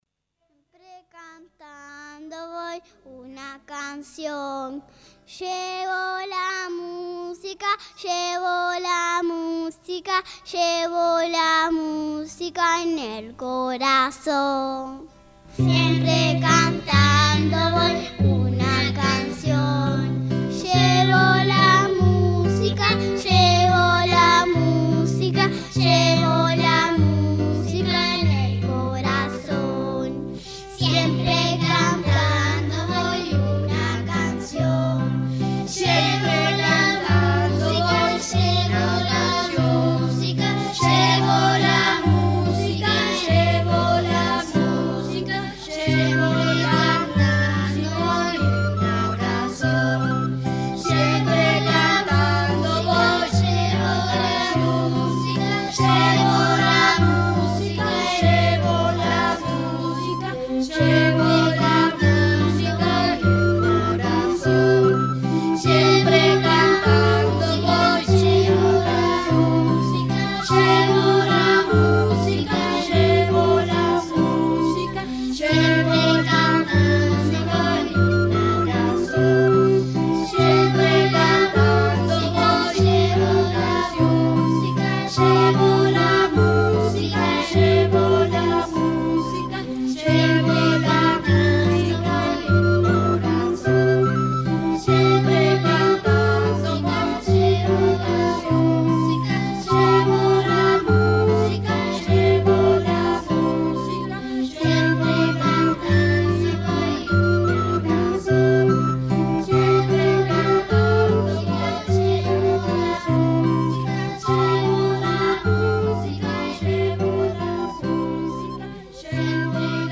Este es un viejo canon y quodlibet alemán que en esta oportunidad lo versionamos en español